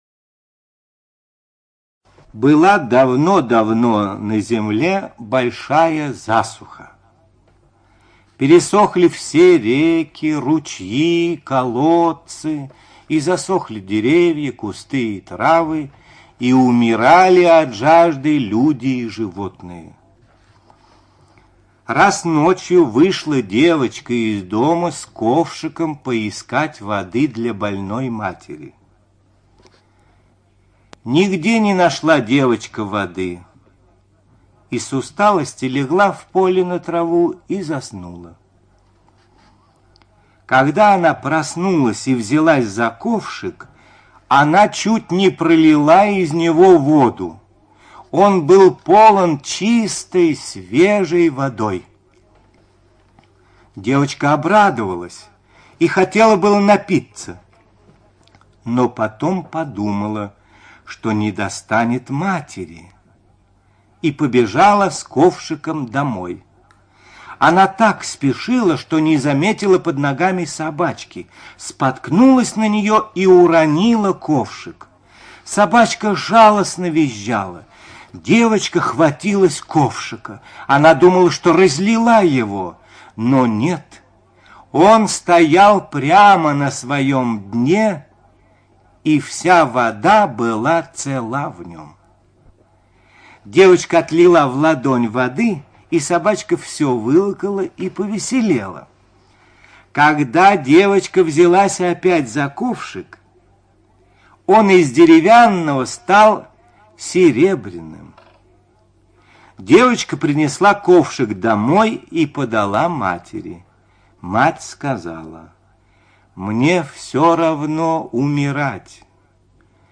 ЧитаетОрлов Д.